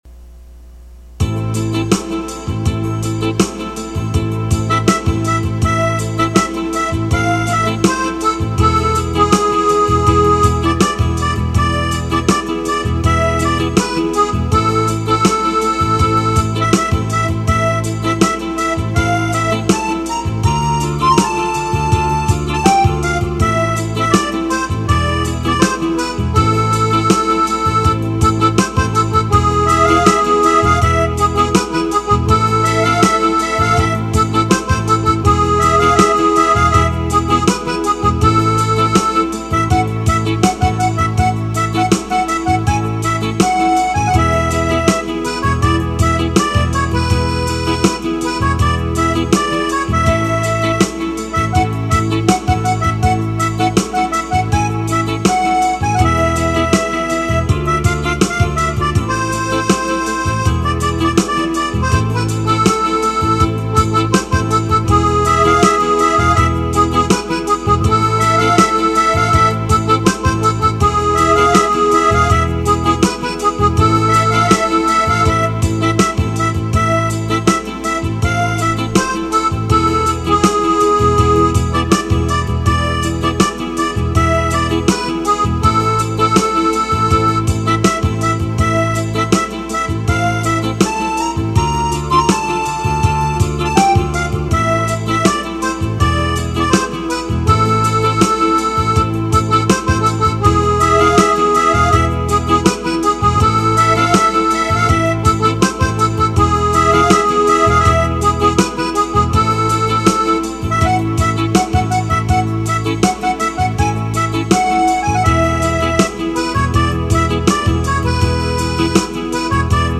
Le TRK est le fichier midi en format mp3 sans la mélodie.